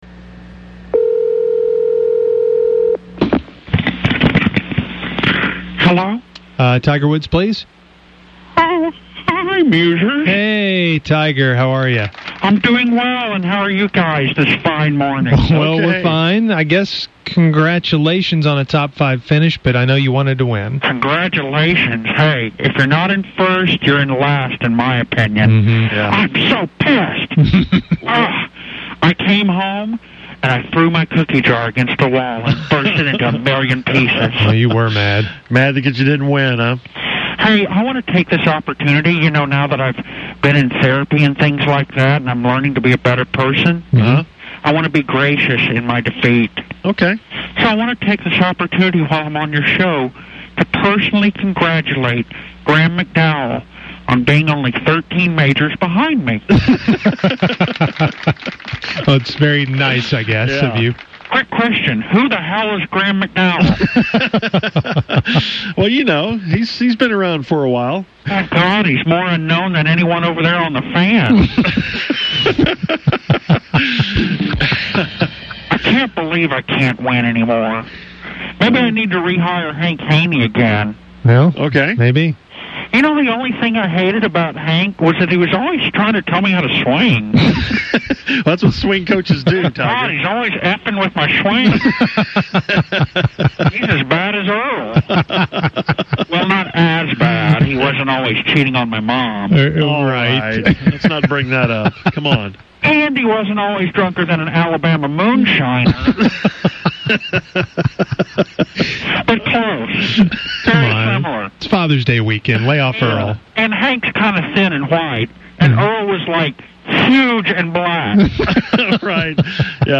Musers talk to the fake Tiger about the US Open. Tiger sounds really happy, but really angry.